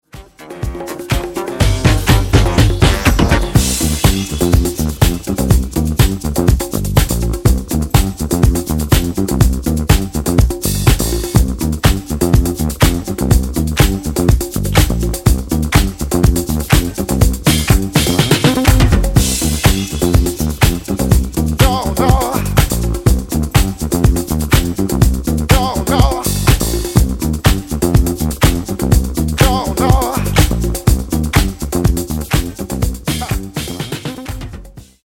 Genere:   Disco|Soul | Funky